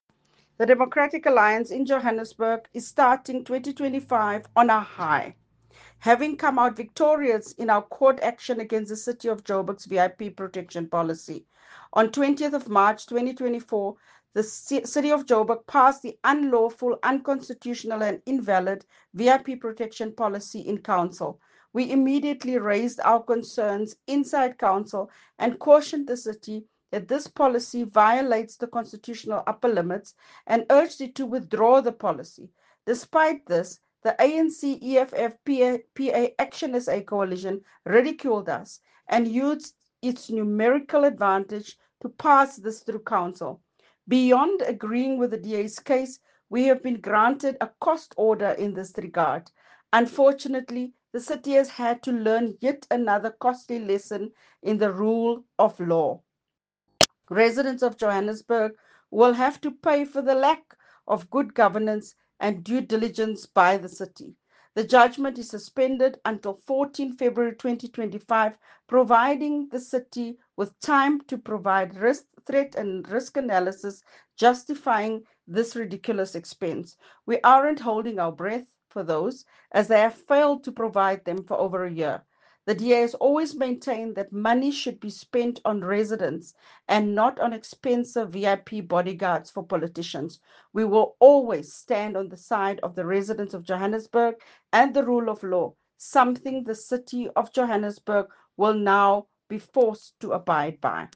Note to Editors: Please find an English soundbite by Cllr Belinda Kayser-Echeozonjoku